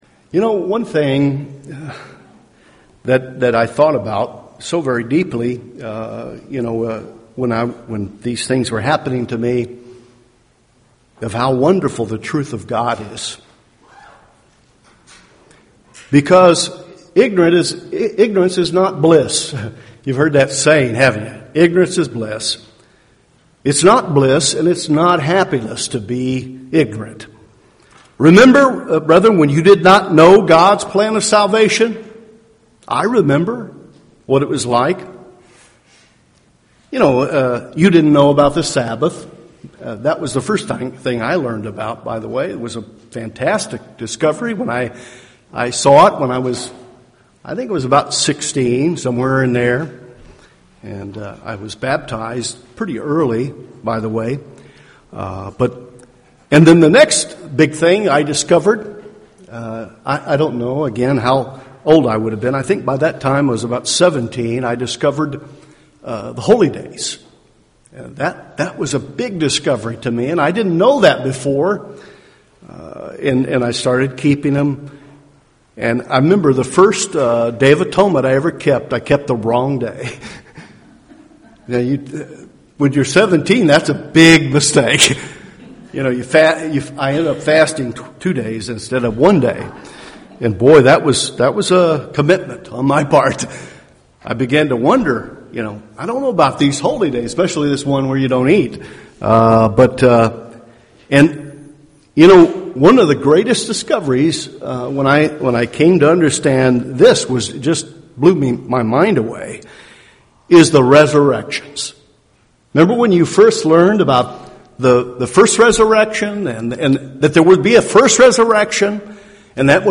This sermon explains how we must apply this truth.